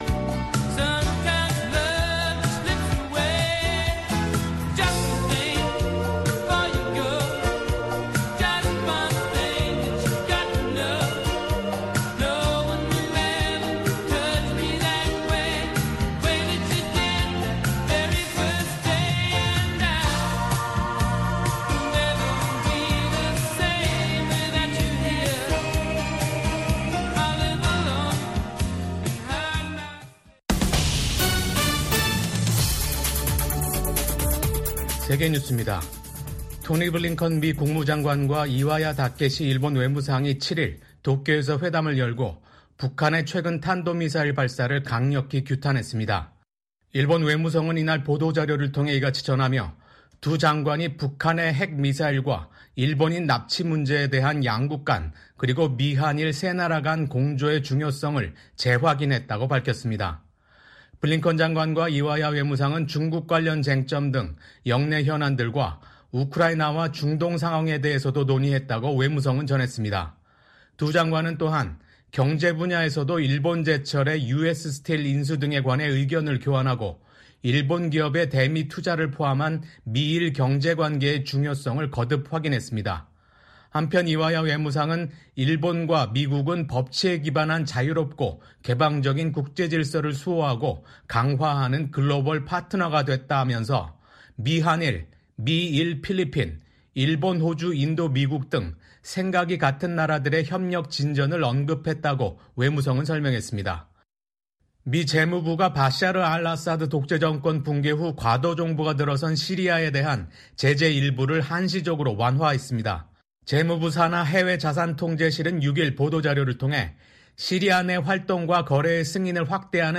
VOA 한국어 아침 뉴스 프로그램 '워싱턴 뉴스 광장'입니다. 북한은 어제(6일) 신형 극초음속 중장거리 탄도미사일 시험발사에 성공했다며 누구도 대응할 수 없는 무기체계라고 주장했습니다. 미국과 한국의 외교장관이 북한의 탄도미사일 발사를 강력히 규탄했습니다.